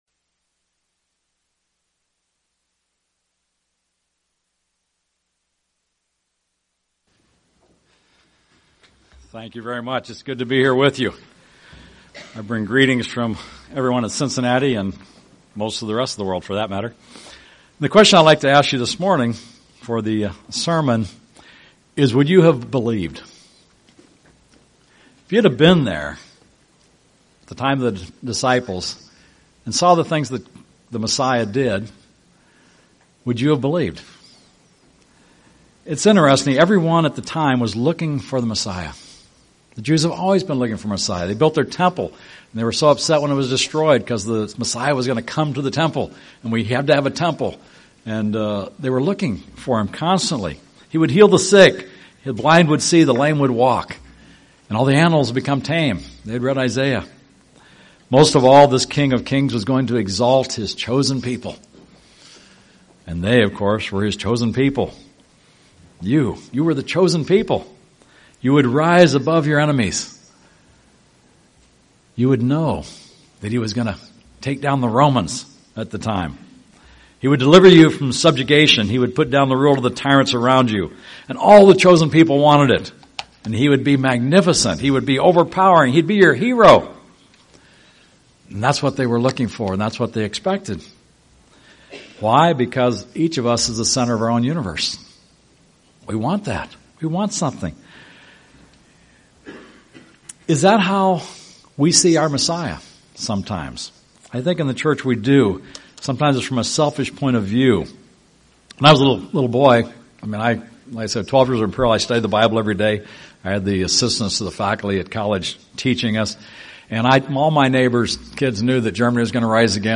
Holy Day Services Transcript This transcript was generated by AI and may contain errors.